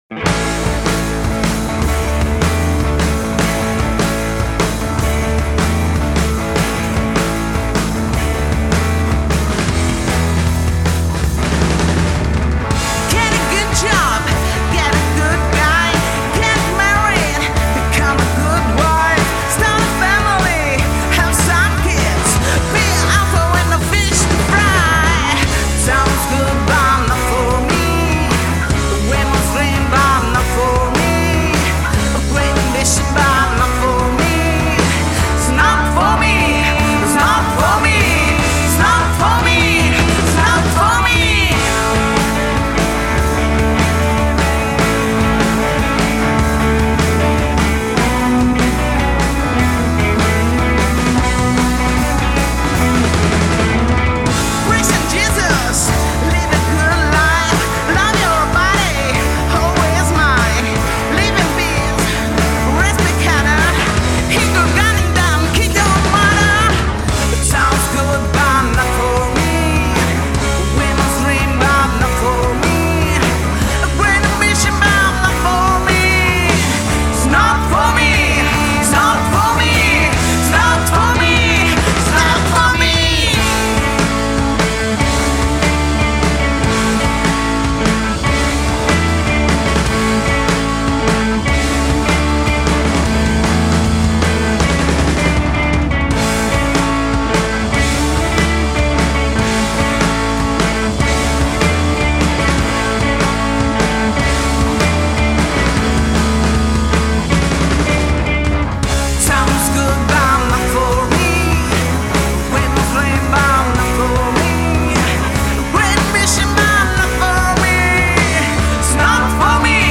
restituisce un sound contemporaneo e d’impatto